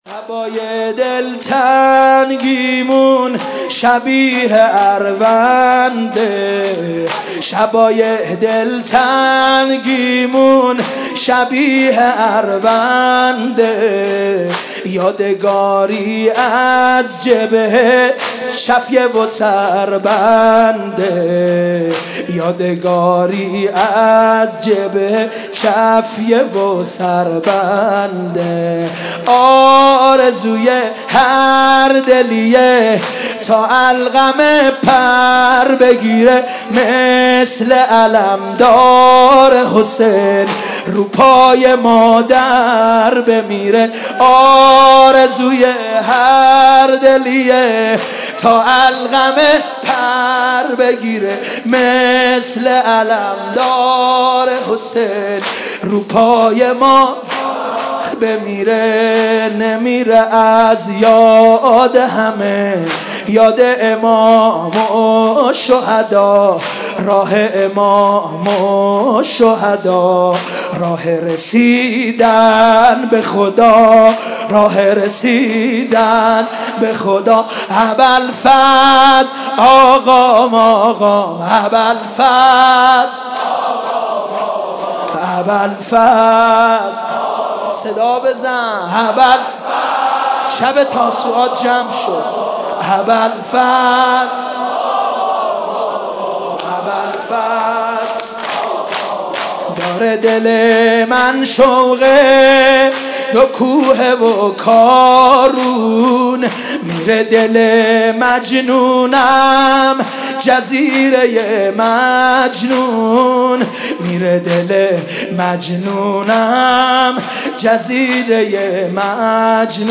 maddahi-209.mp3